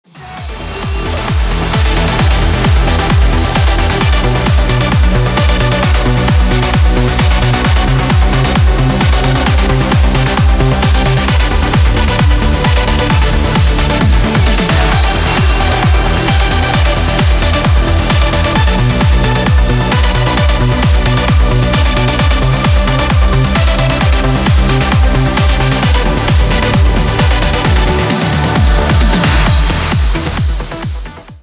Стиль: Vocal Trance Ура!